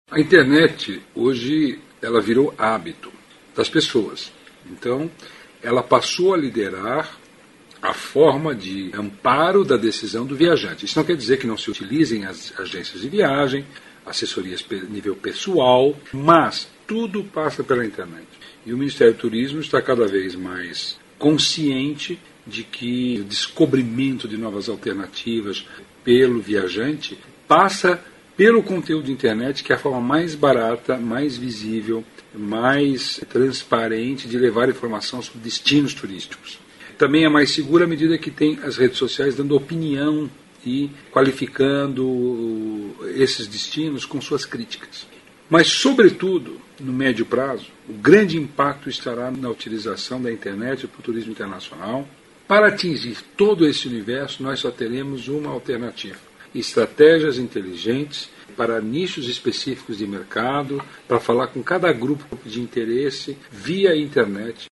aqui para ouvir declaração do secretário Vinicius Lummertz sobre a importância da internet na escolha de destinos.